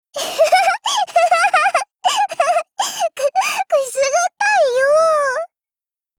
贡献 ） 协议：Copyright，人物： 碧蓝航线:阿蒂利奥·雷戈洛语音 您不可以覆盖此文件。